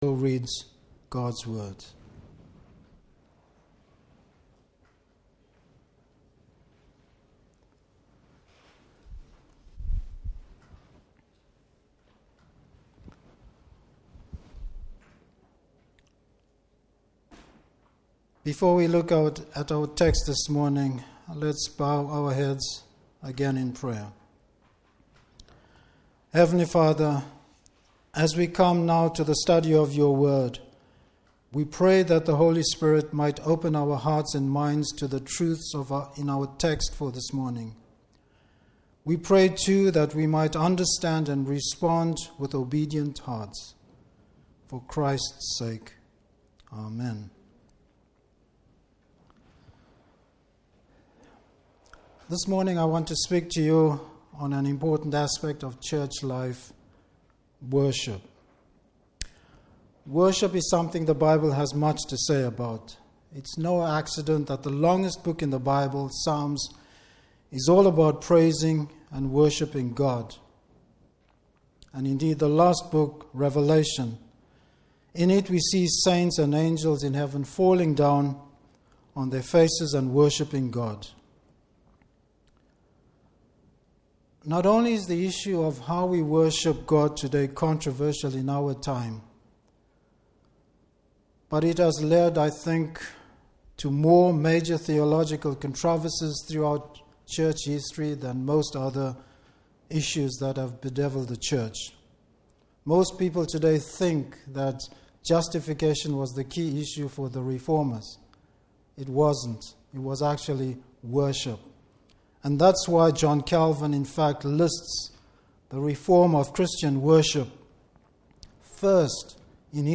Service Type: Morning Service What does it mean to worship in spirit and truth?